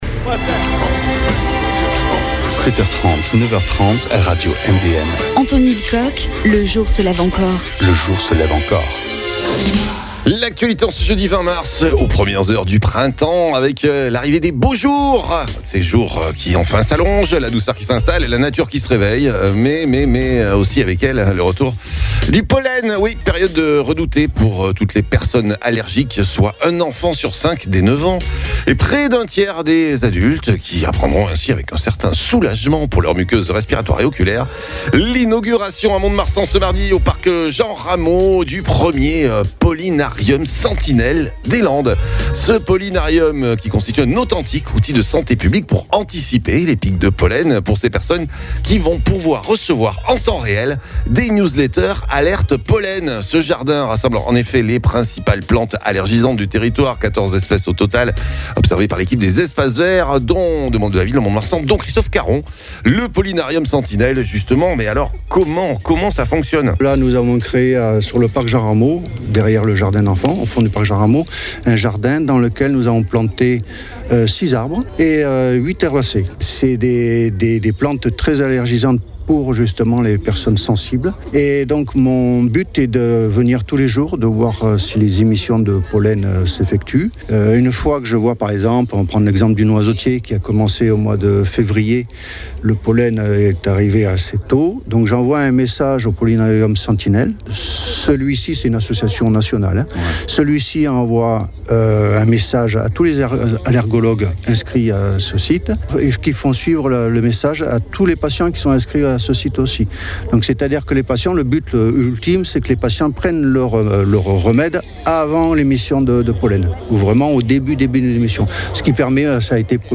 Présentation